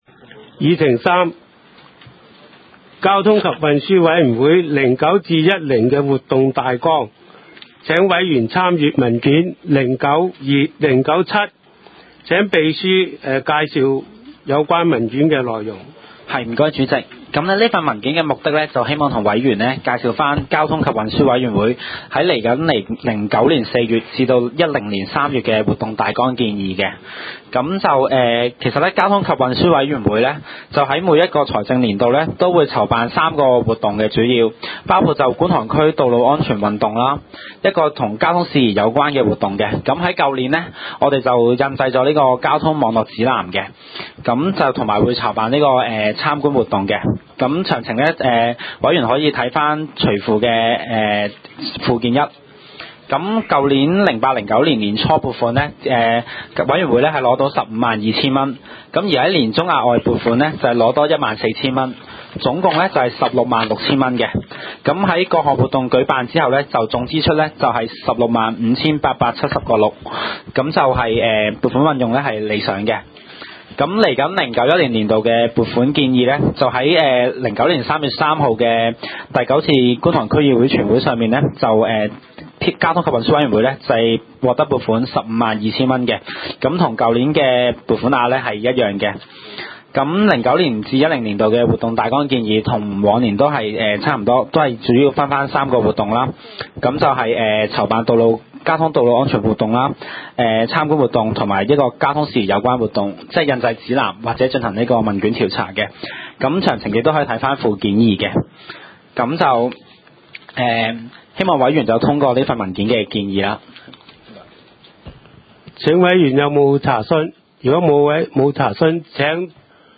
第三屆觀塘區議會屬下 交通及運輸委員會第 十 次會議 記 錄 日 期 : 2009 年 4 月 2 日 ( 星期二 ) 時 間 : 下午 2 時 30 分 地 點 : 九龍觀塘同仁街 6 號觀塘政府合署 3 樓觀塘民政事務處會議室 議 程 討論時間 I. 通過上次會議記錄 0:00:57 II. 中九龍幹線進度報告 0:21:49 III. 交通及運輸委員會 2009 至 2010 年度活動大綱建議 0:03:00 IV.